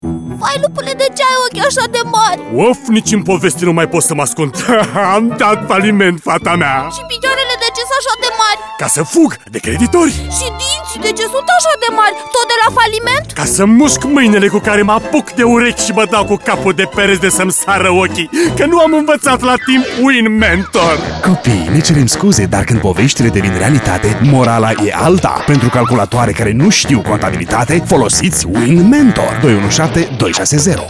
SPOTURI RADIO